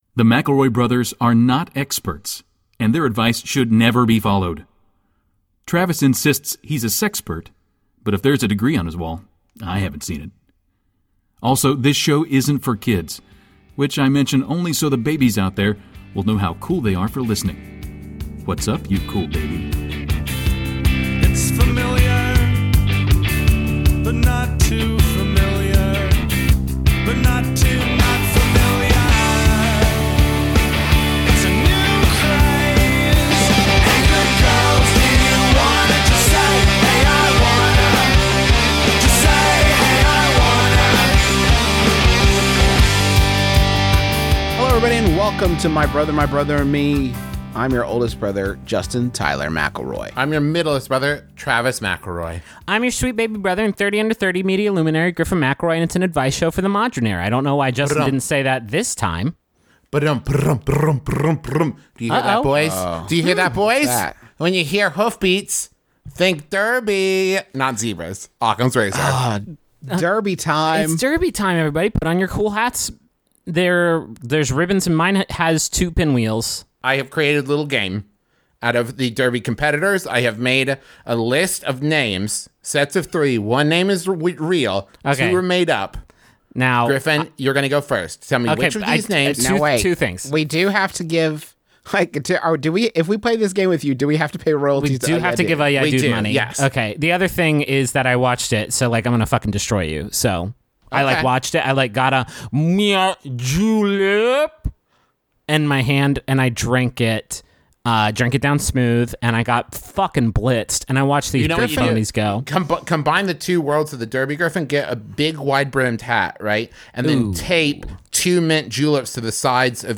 Mbmbam, Mcelroy Brothers, Advice, Justin Mcelroy, Travis Mcelroy, Comedy Advice, Mcelroy, Griffin Mcelroy, Comedy